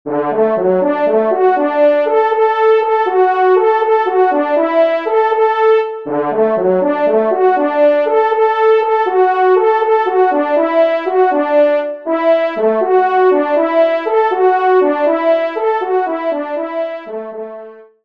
Genre :  Divertissement pour Trompes ou Cors
Pupitre 1°  Cor